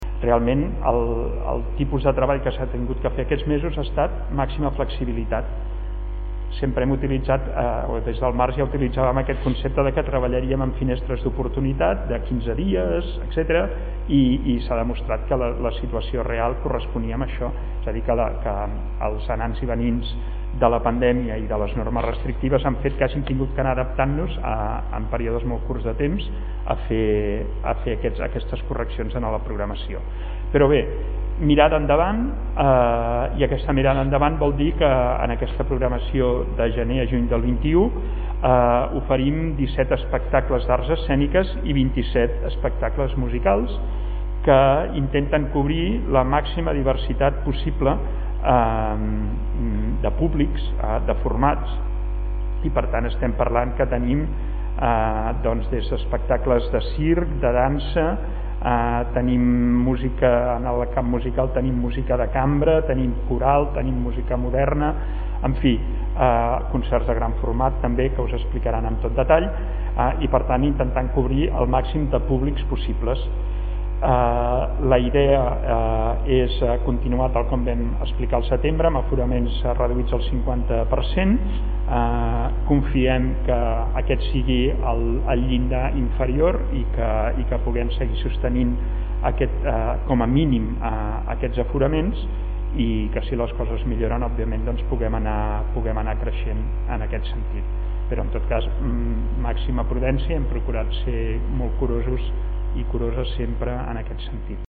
Fitxers relacionats Dossier de premsa programació Nadal i gener-juny 2021 LaTemporada Lleida (1.4 MB) Tall de veu del regidor de Ciutat i Cultura, Jaume Rutllant, sobre la programació de LaTemporada Lleida (1.6 MB)
tall-de-veu-del-regidor-de-ciutat-i-cultura-jaume-rutllant-sobre-la-programacio-de-latemporada-lleida